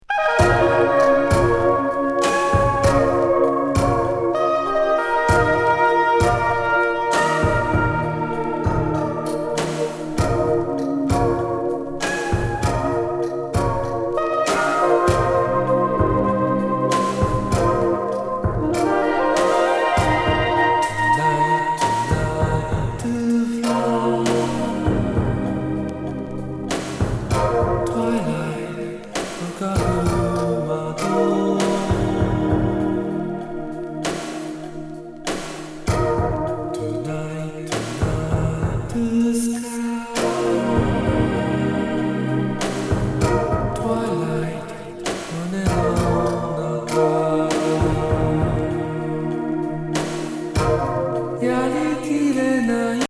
アルペシオ・クラシック